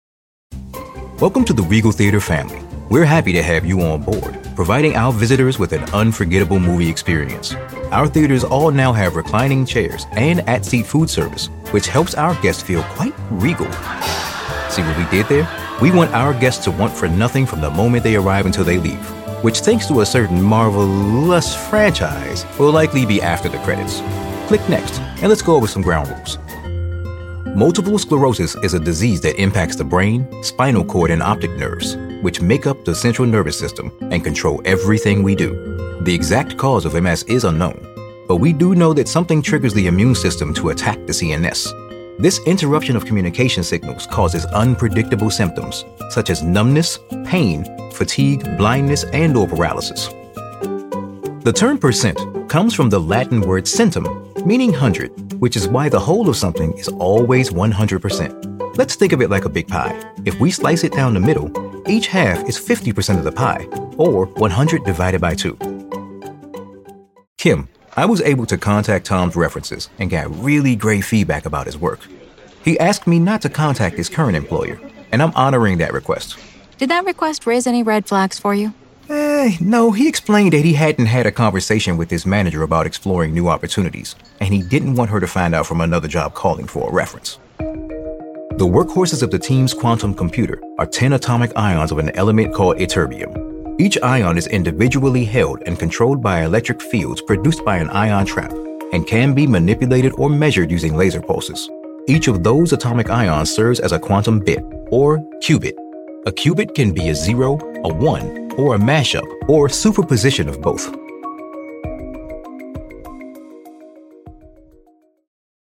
Powerful, Motivating, Best Buddy
eLearning